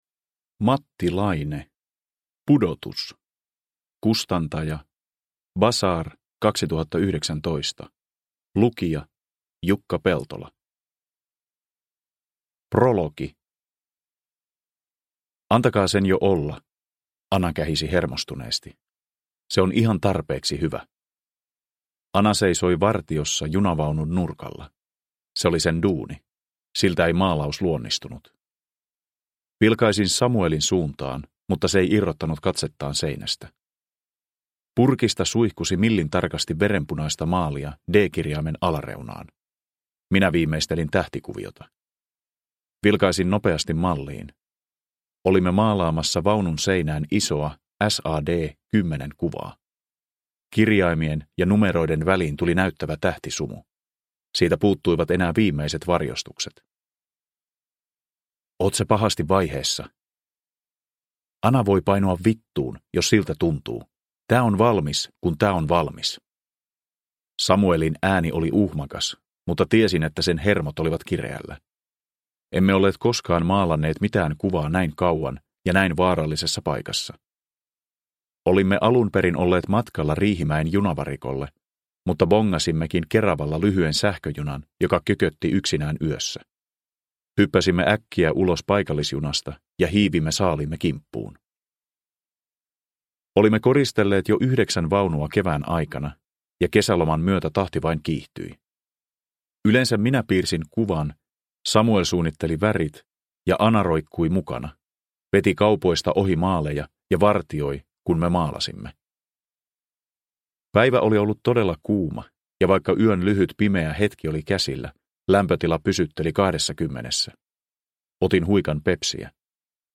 Pudotus – Ljudbok – Laddas ner